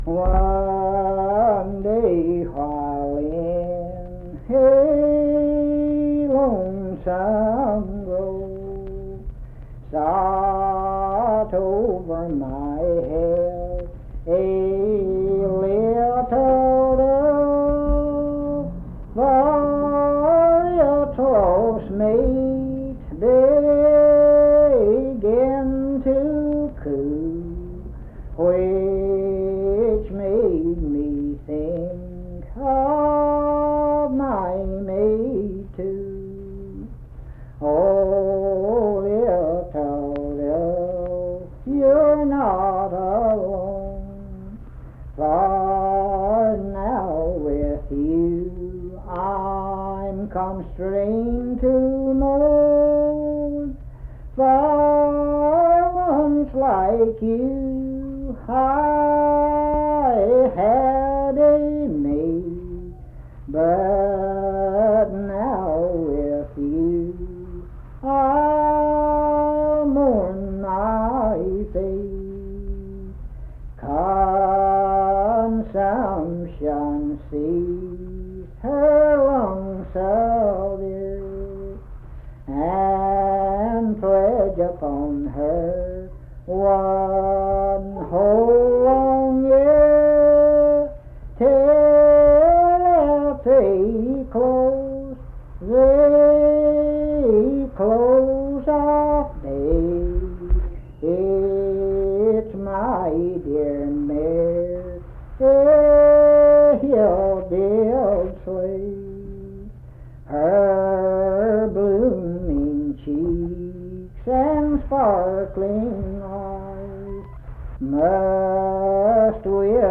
Unaccompanied vocal music
Verse-refrain 6(4).
Performed in Naoma, Raleigh County, WV.
Voice (sung)